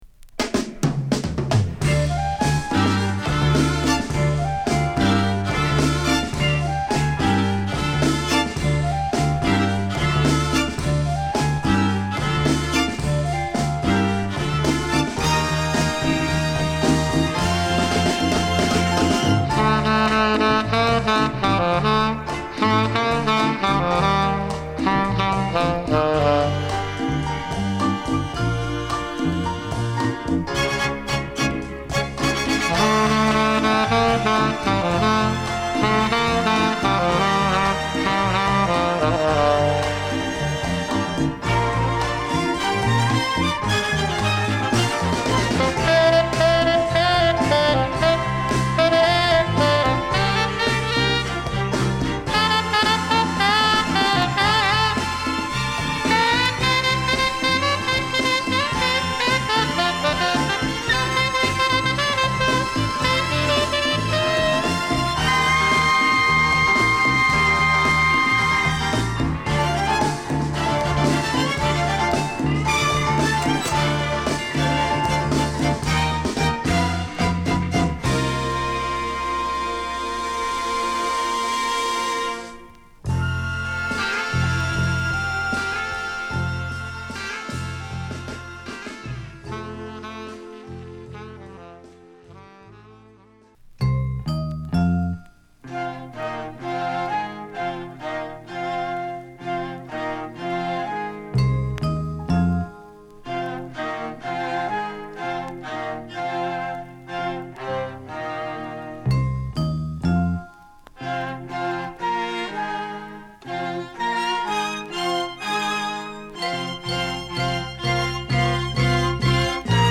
アルバム通してド渋です！